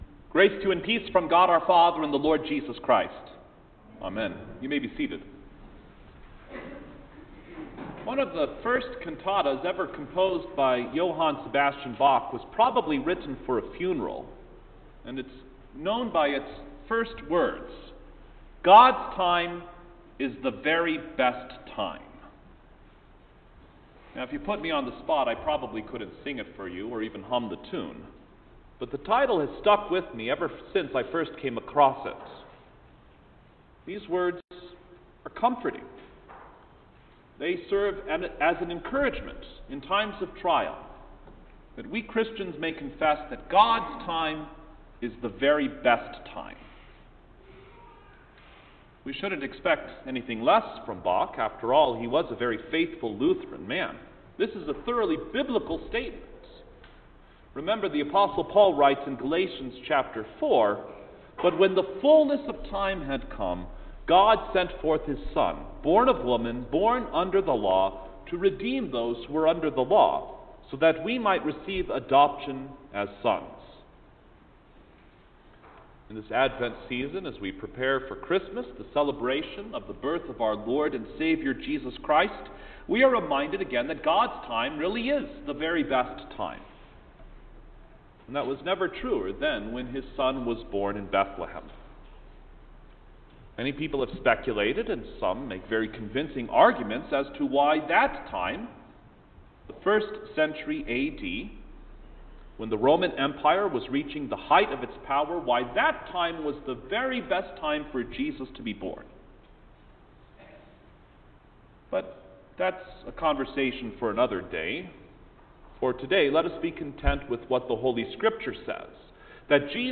December-6_2020_Second-Sunday-In-Advent_Sermon.mp3